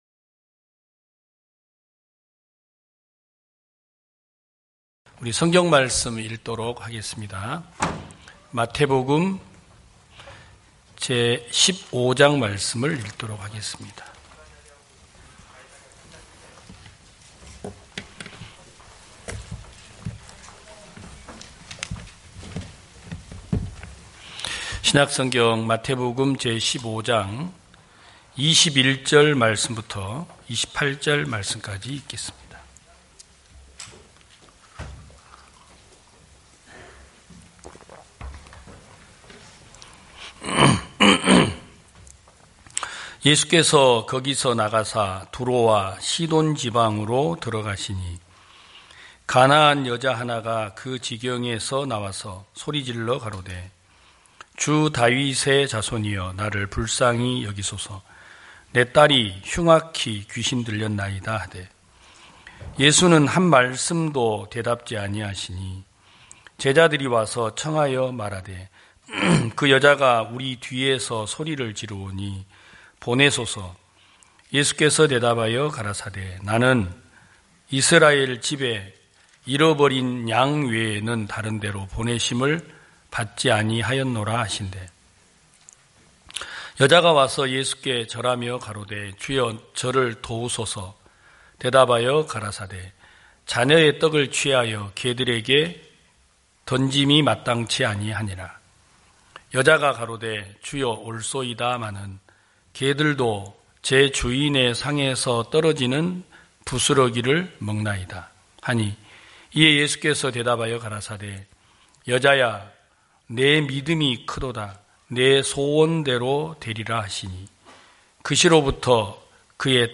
2021년 12월 12일 기쁜소식부산대연교회 주일오전예배